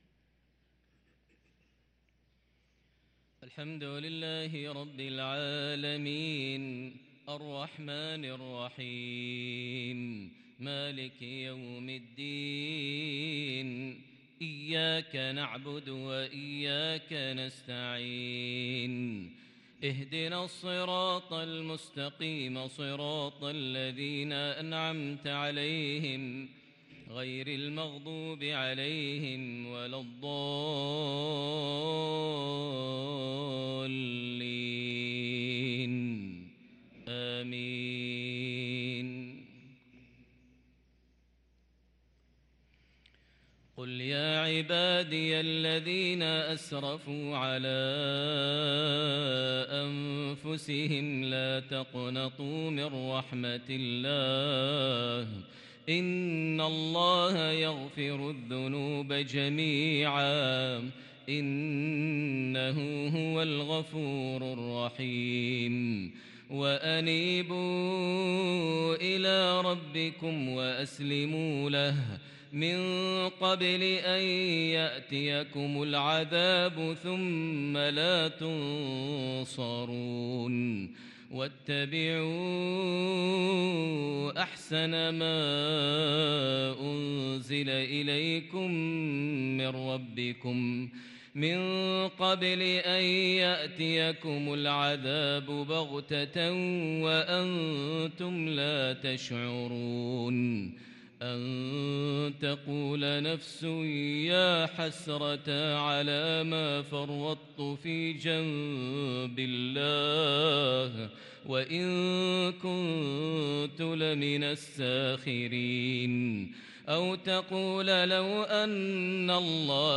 صلاة الفجر للقارئ ماهر المعيقلي 22 محرم 1444 هـ
تِلَاوَات الْحَرَمَيْن .